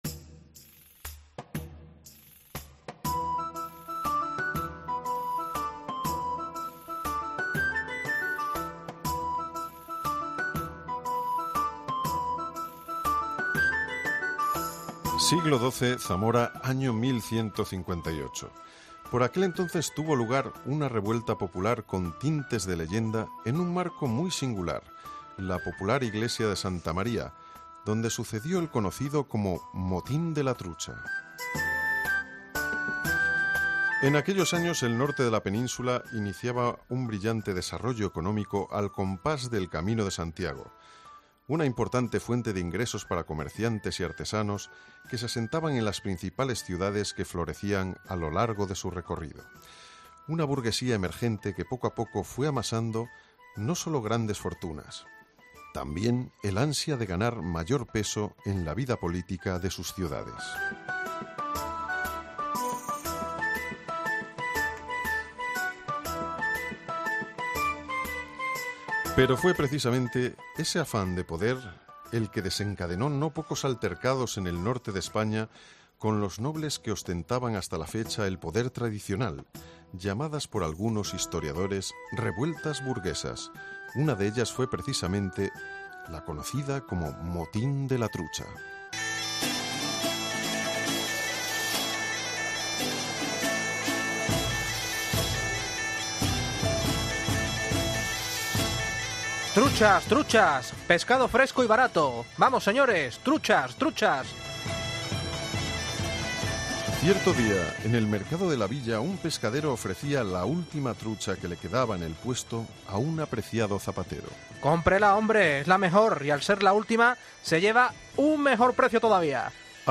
REPARTO